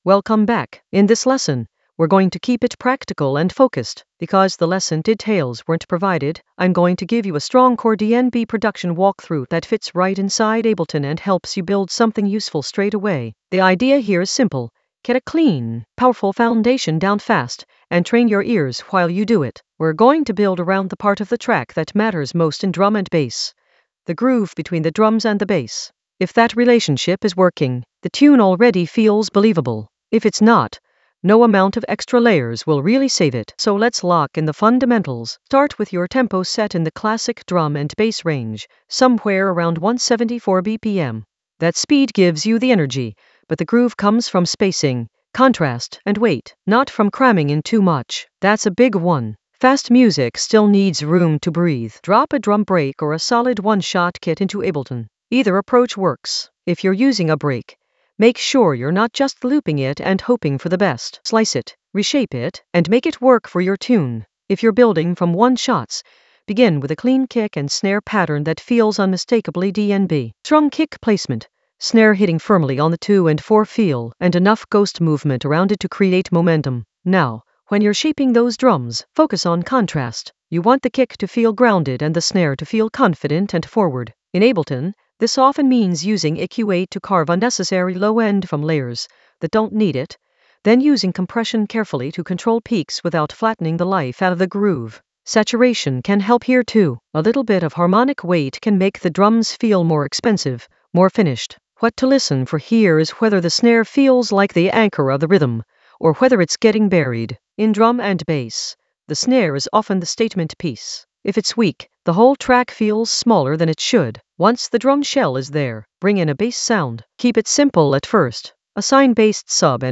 An AI-generated intermediate Ableton lesson focused on Grooverider sub basslines that rattle in the Basslines area of drum and bass production.
Narrated lesson audio
The voice track includes the tutorial plus extra teacher commentary.